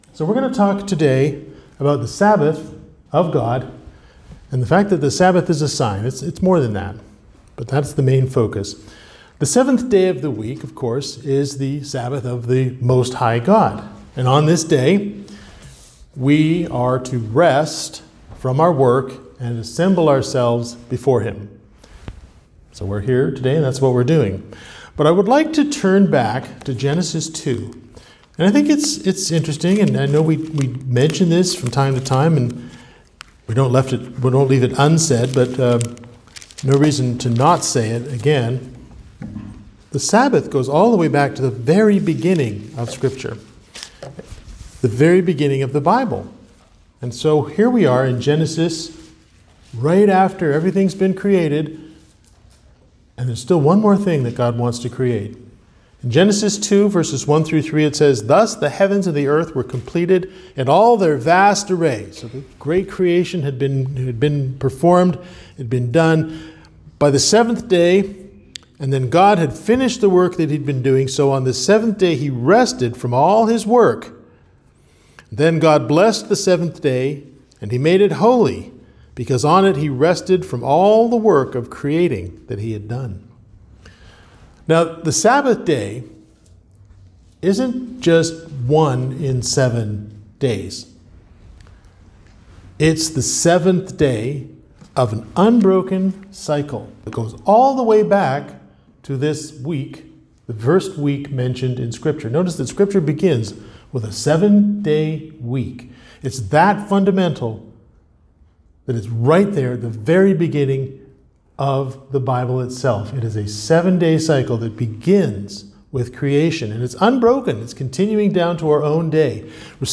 Sermons
Given in Greensboro, NC Raleigh, NC